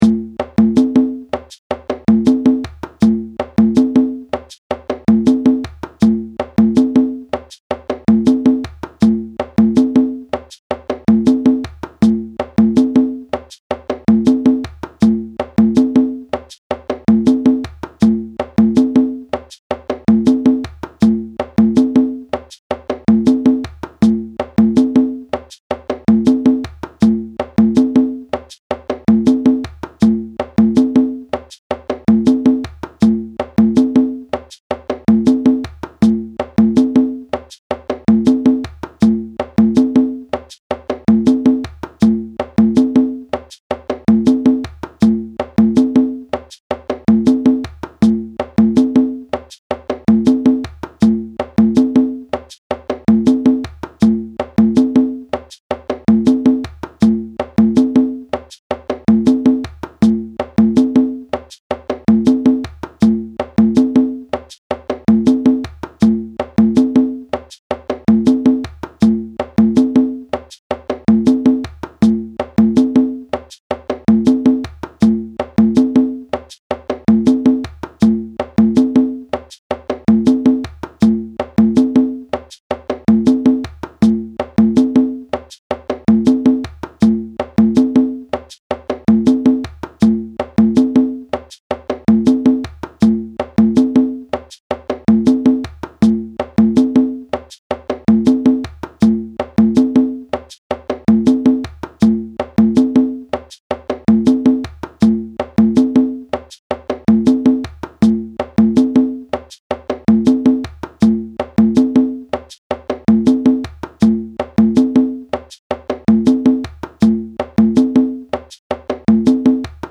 audio (with shekeré)
2-Drum Rhythms (44) are played by one person on two drums.
Mozambique-N.Y.-slow-hh.mp3